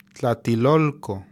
modern Nahuatl pronunciation) (also called Mexico Tlatelolco) was a pre-Columbian altepetl, or city-state, in the Valley of Mexico.